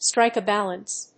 アクセントstríke a bálance (betwèen…)